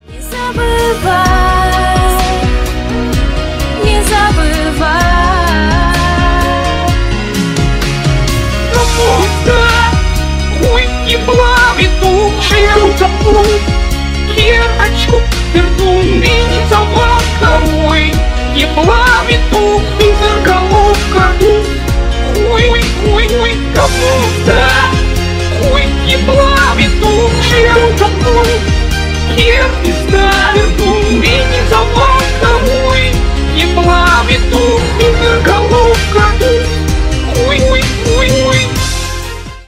дуэт
ремиксы